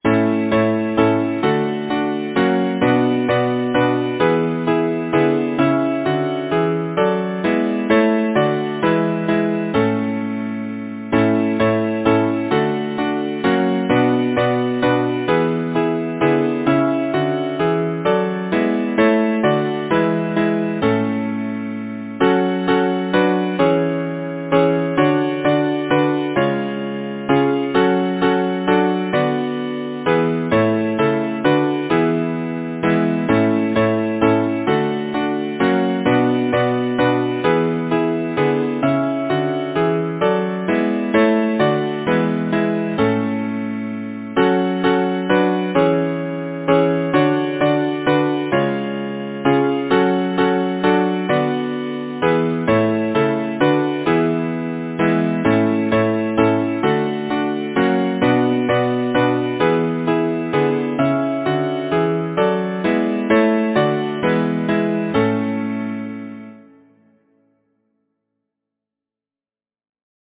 Number of voices: 4vv Voicing: SATB Genre: Secular, Partsong, Madrigal
Language: English Instruments: A cappella (keyboard reduction ad lib.)